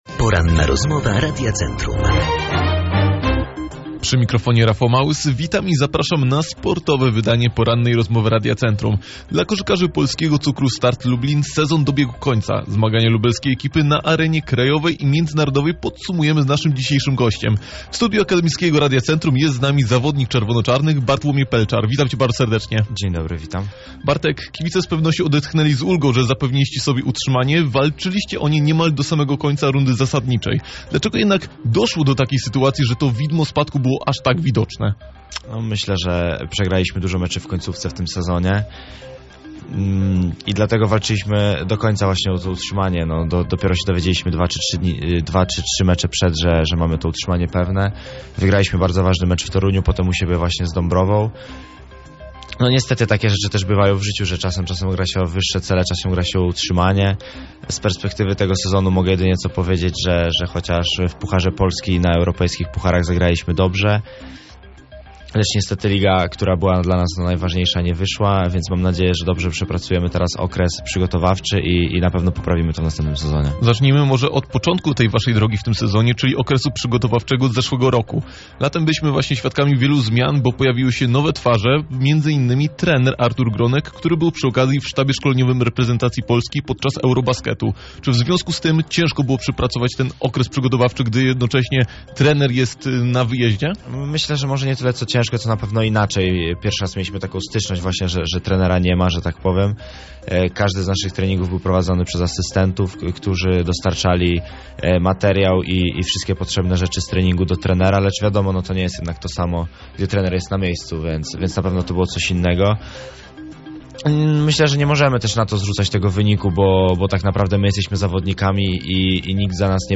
W dzisiejszym programie podsumował sezon w wykonaniu swojego zespołu zarówno na krajowym podwórku, jak i arenie międzynarodowej. Cała rozmowa dostępna poniżej.